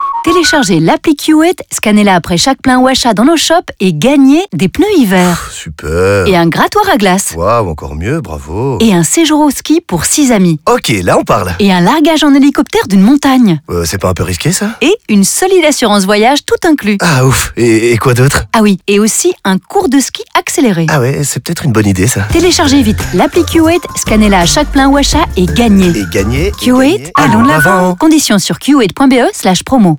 Pour informer les futurs gagnants que le concours est en cours, VML a opté pour des spots radio & des pubs sur les réseaux sociaux & de la communication POS.